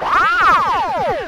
alarm.ogg